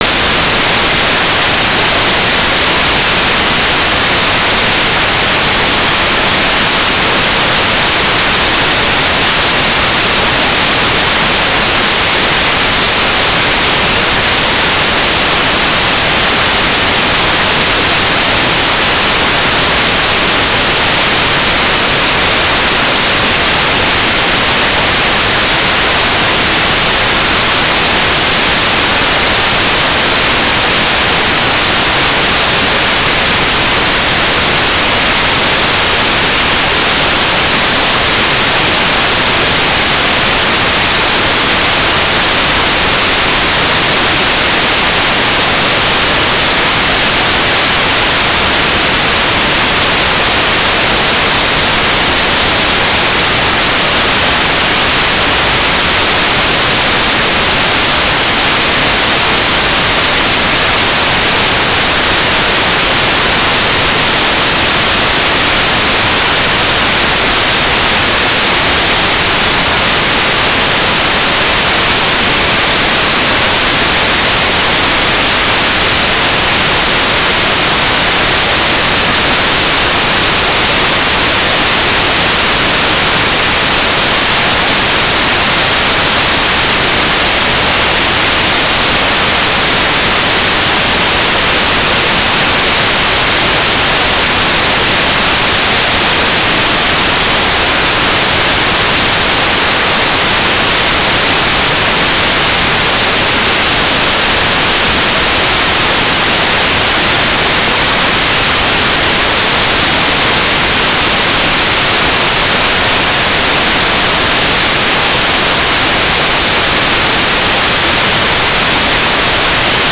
"transmitter_description": "Mode V APRS",
"transmitter_mode": "AFSK",
"transmitter_baud": 1200.0,